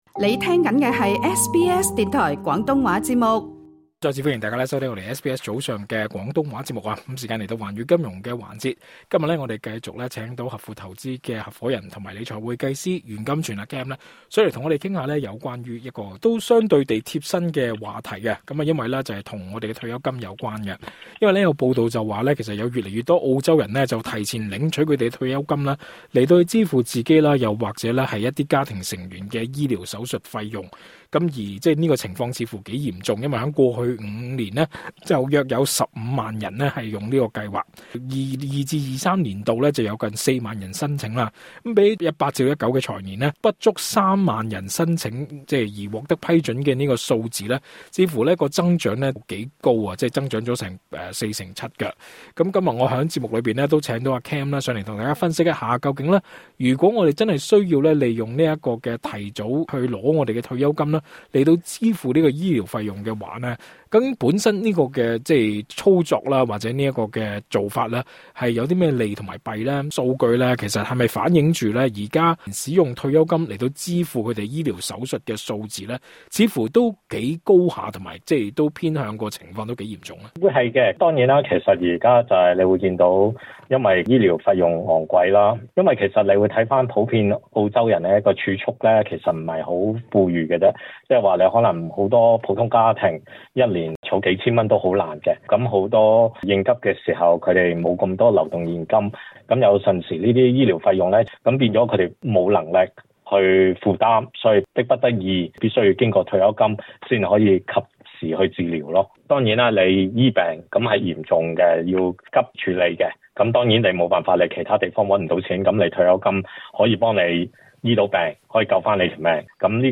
足本訪問：